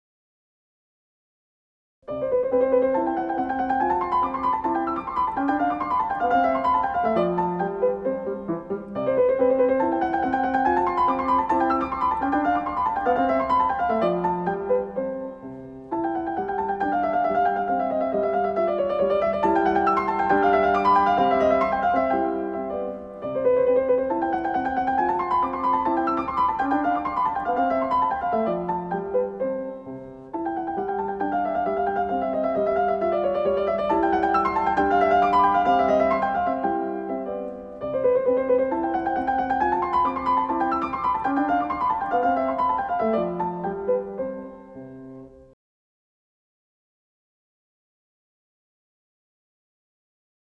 Un buen ejemplo de tema con variaciones son las variaciones para piano K. 265 de Mozart, sobre la conocidísima canción “Ah, vous dirais-je maman”.